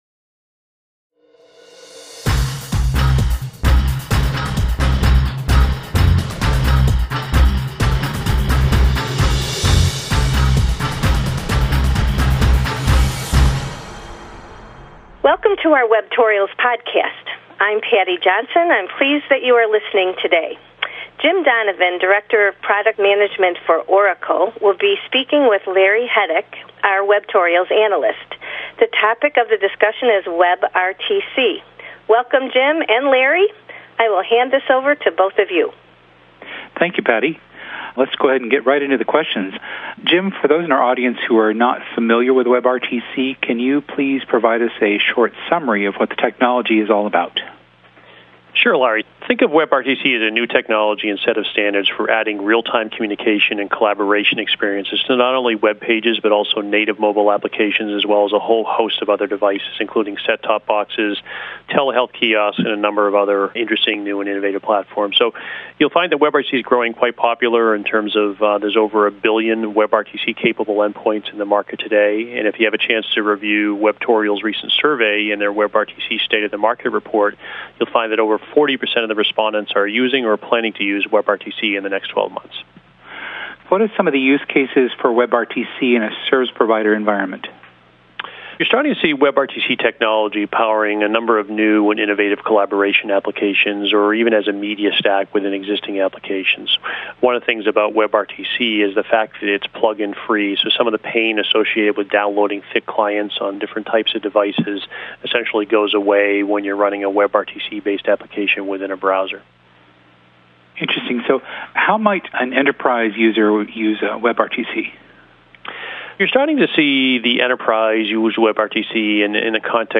This webcast, available as an audio file or a transcript, captures a recent discussion about WebRTC